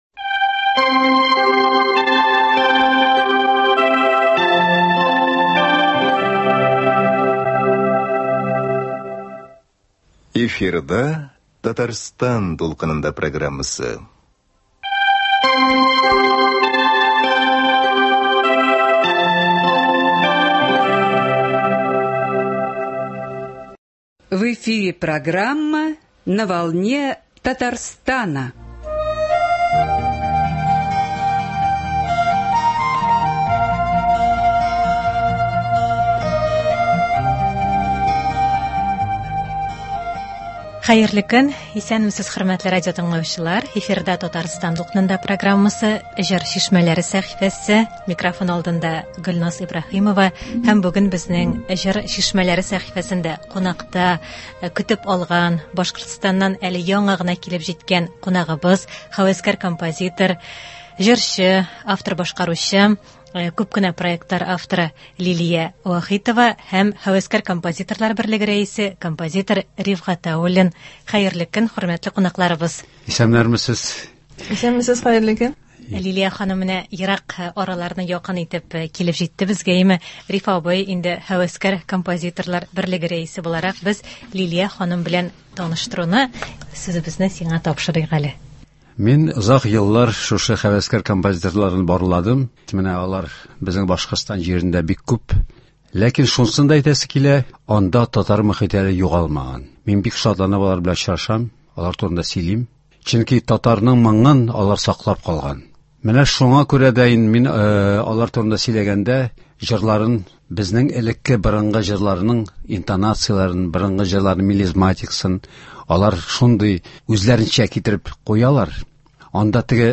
Студия кунагы